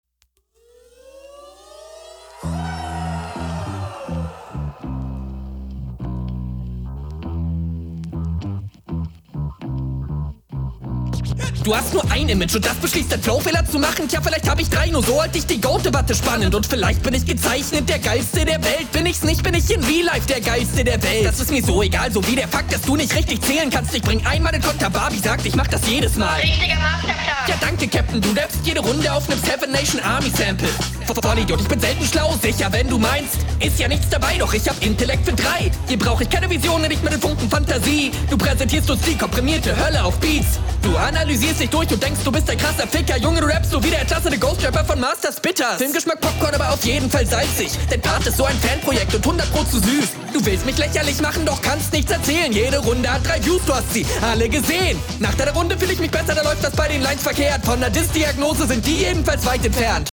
Acapella ist zu laut abgemischt.